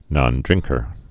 (nŏn-drĭngkər)